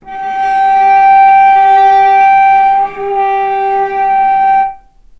vc_sp-G4-mf.AIF